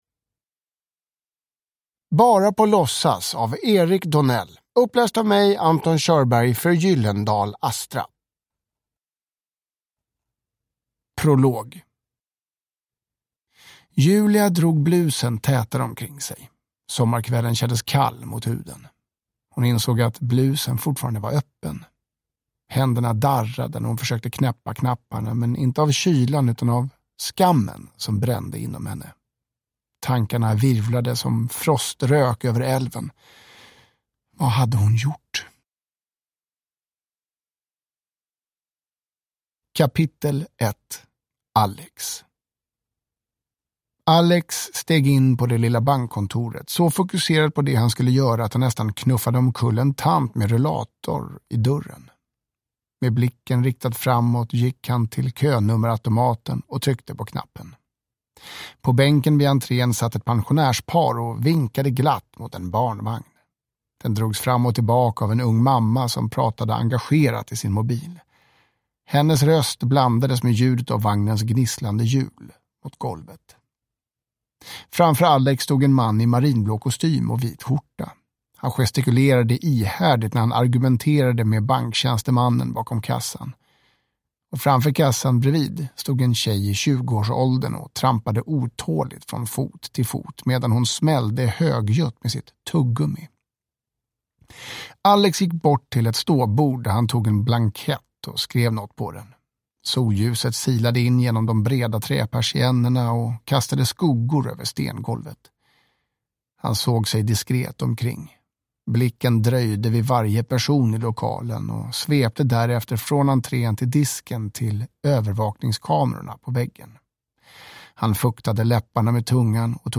Bara på låtsas – Ljudbok
Feelgood Njut av en bra bok